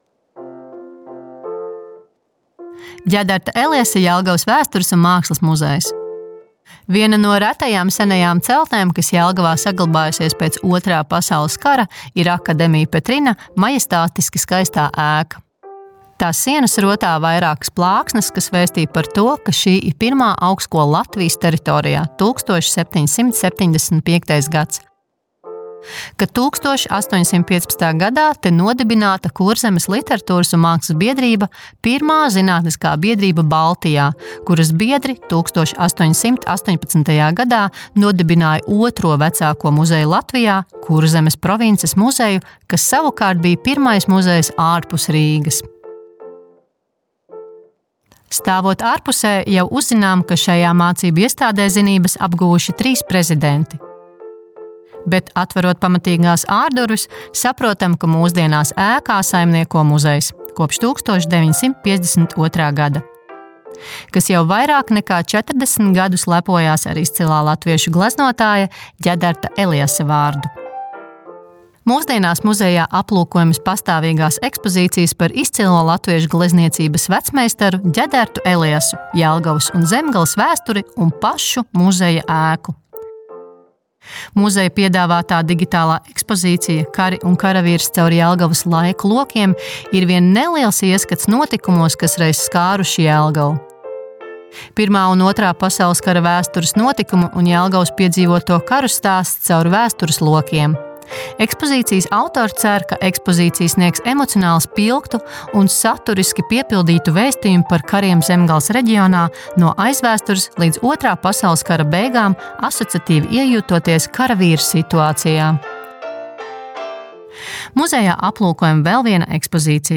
AUDIO STĀSTĪJUMS